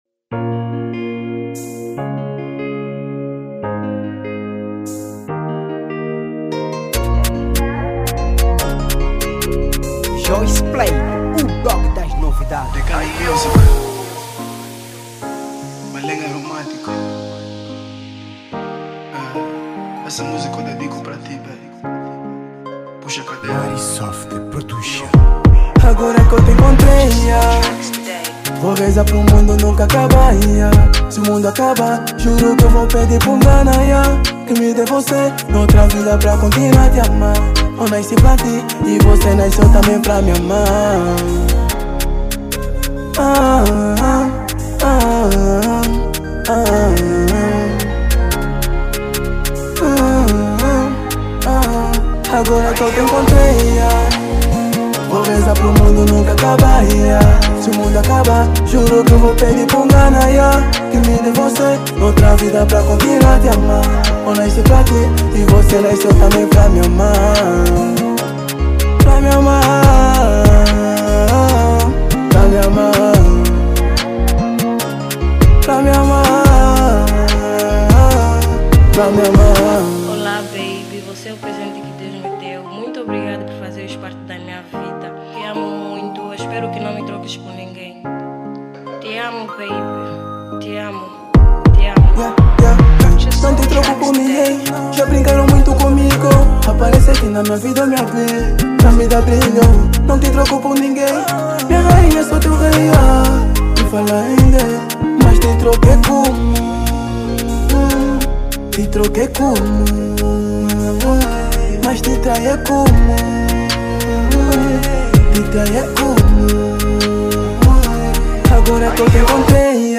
Categoria: Afro Trap